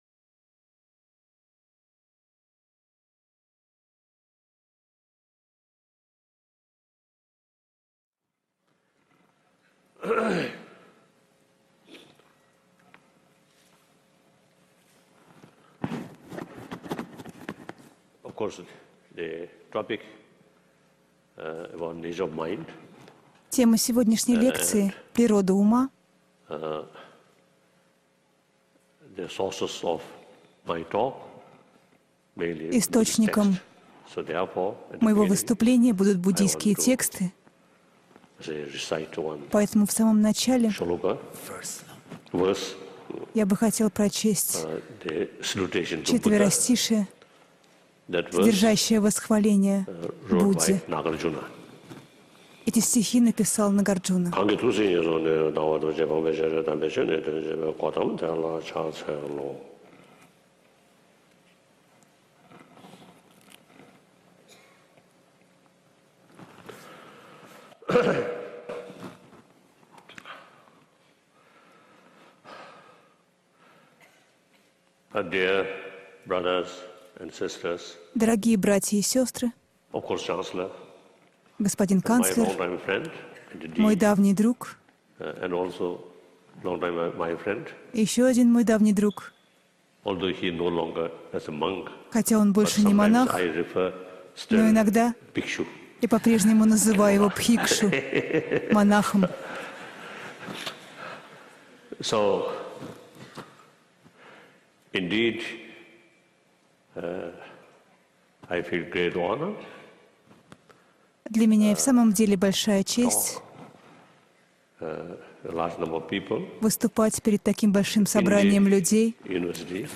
Аудиокнига Далай-лама о природе ума | Библиотека аудиокниг